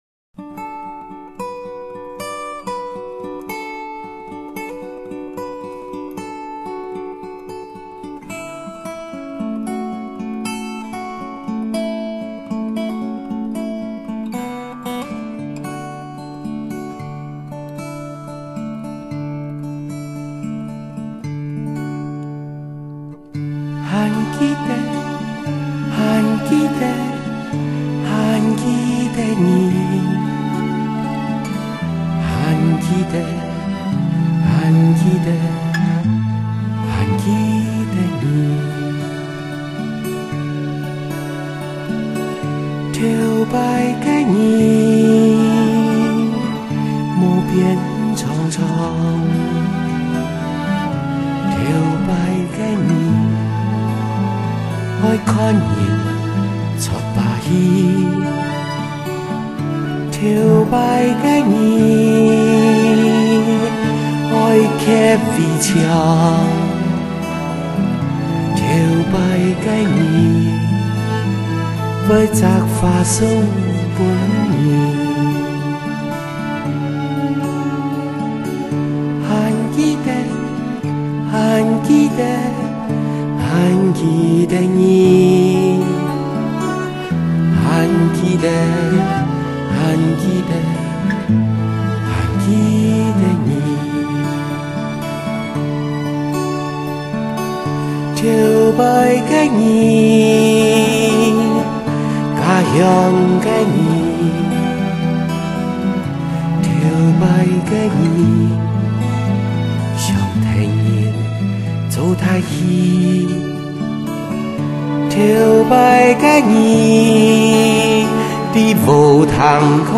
专辑中的歌曲绝大多数以钢弦民谣吉他伴奏 为主，辅以少量的弦乐、口琴敲击乐器及和声，音响画面简洁明了。